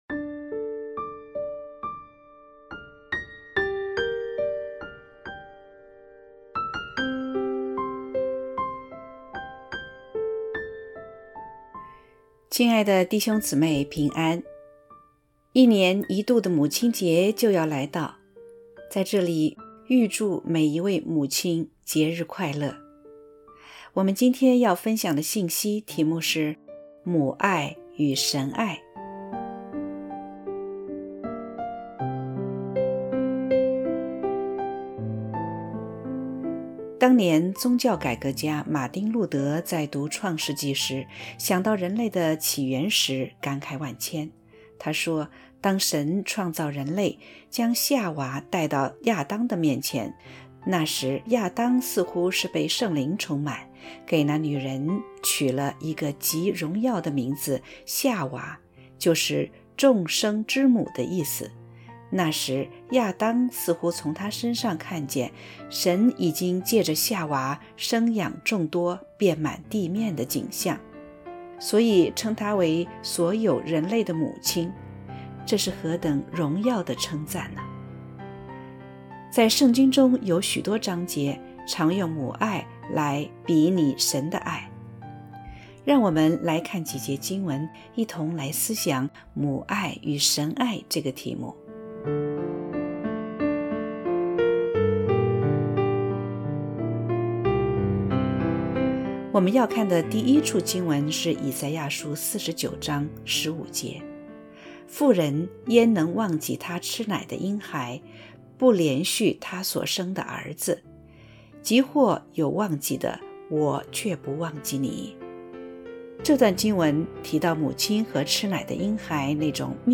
（合成）母爱与神爱.mp3